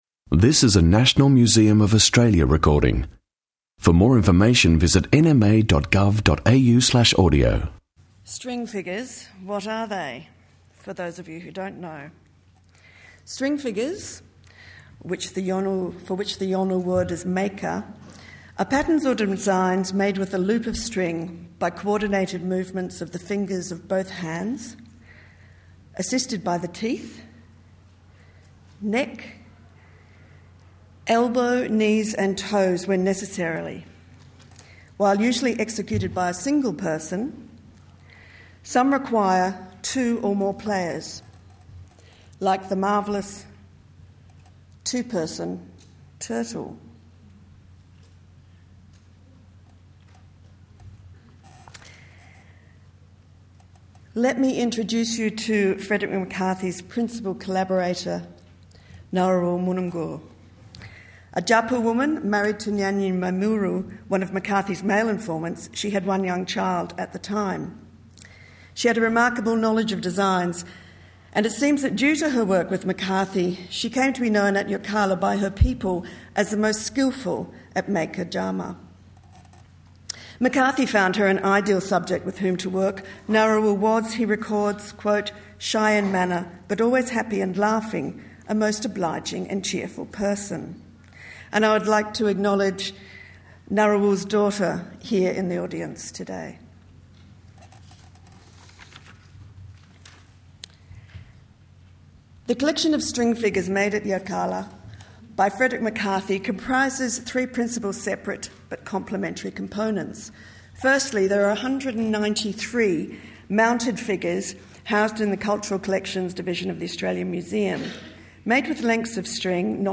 Barks, Birds and Billabongs symposium 19 Nov 2009